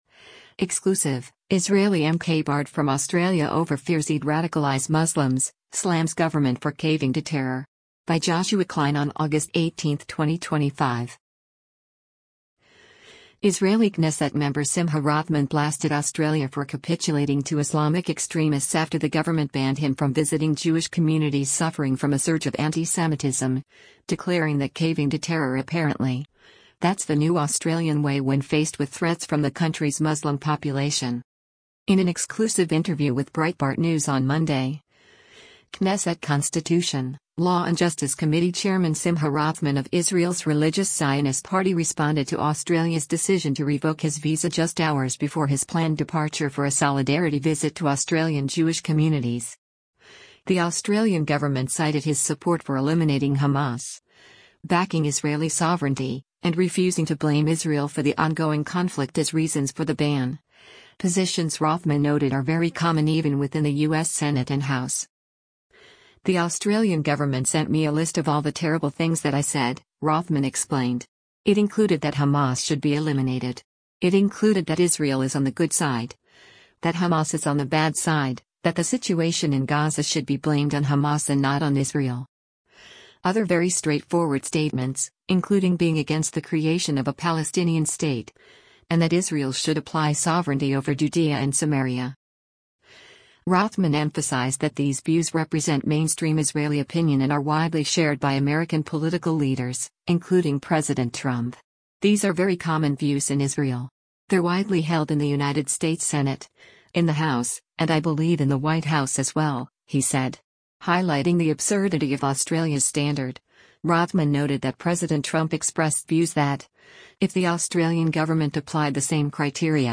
In an exclusive interview with Breitbart News on Monday, Knesset Constitution, Law and Justice Committee Chairman Simcha Rothman of Israel’s Religious Zionist party responded to Australia’s decision to revoke his visa just hours before his planned departure for a solidarity visit to Australian Jewish communities.